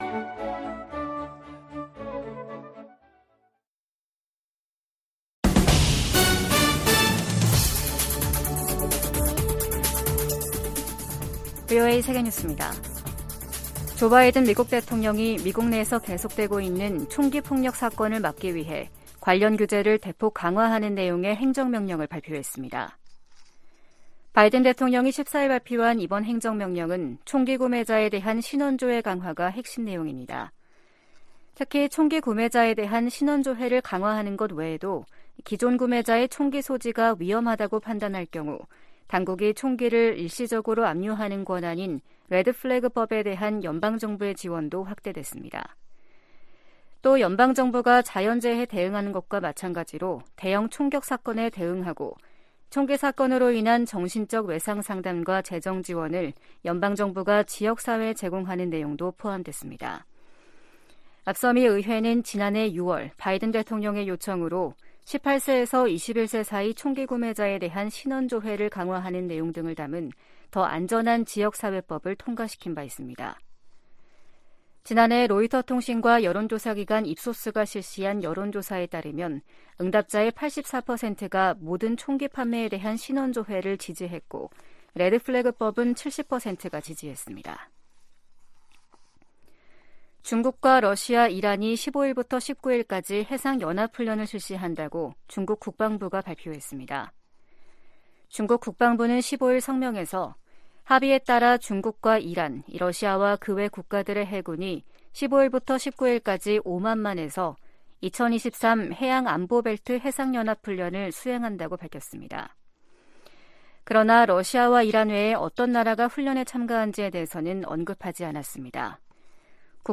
VOA 한국어 아침 뉴스 프로그램 '워싱턴 뉴스 광장' 2023년 3월 16일 방송입니다. 북한은 14일 황해남도 장연에서 지대지 탄도미사일 2발 사격 훈련을 실시했다고 다음날 관영매체를 통해 발표했습니다. 북한의 최근 미사일 도발이 미한 연합훈련을 방해할 의도라면 성공하지 못할 것이라고 미 백악관이 지적했습니다. 일본 방문을 앞둔 윤석열 한국 대통령은 일본 언론과의 인터뷰에서 북한 핵 위협에 맞서 미한일 협력의 중요성을 강조했습니다.